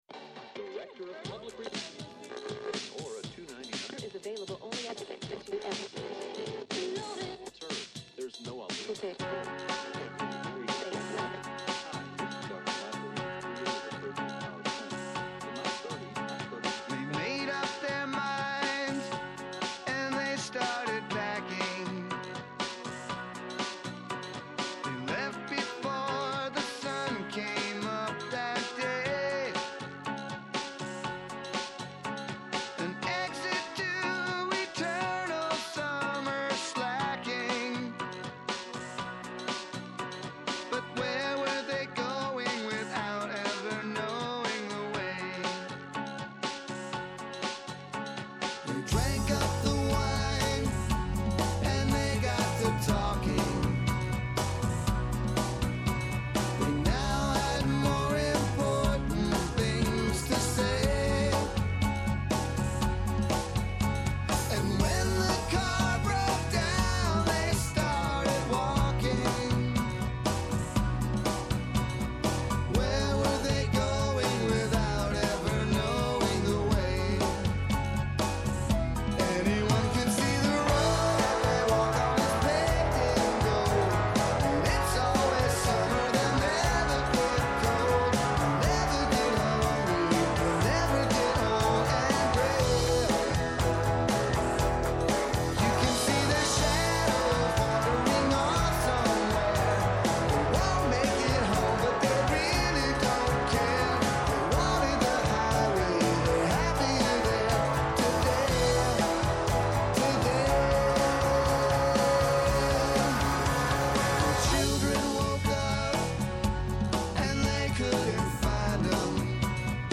Σήμερα καλεσμένος ο Δημήτρης Καιρίδης, Υπουργός Μετανάστευσης και Ασύλου